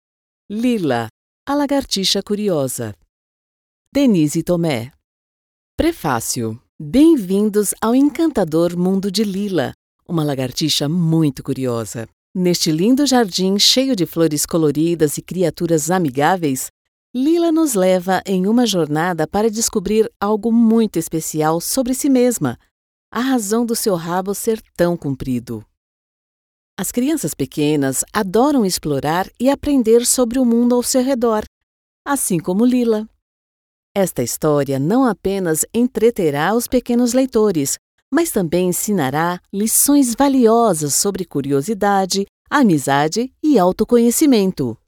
Friendly, Natural, Reliable, Versatile, Corporate
Corporate